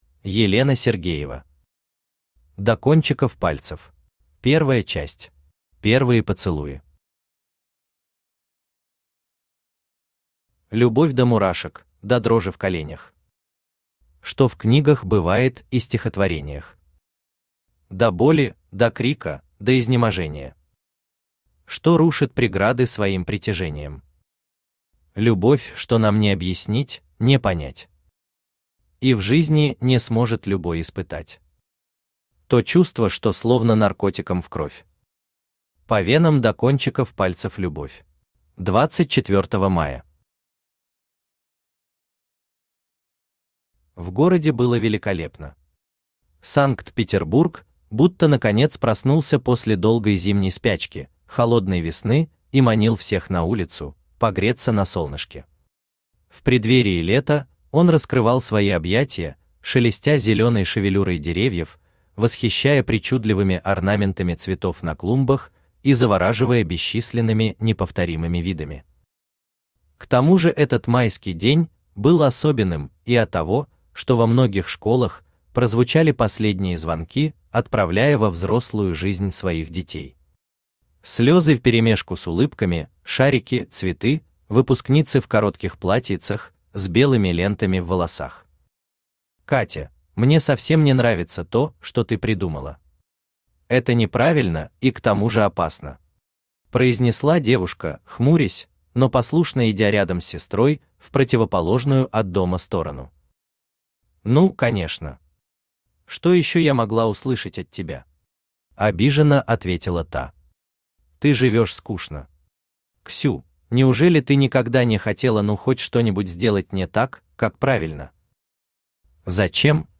Аудиокнига До кончиков пальцев | Библиотека аудиокниг
Aудиокнига До кончиков пальцев Автор Елена Сергеева Читает аудиокнигу Авточтец ЛитРес.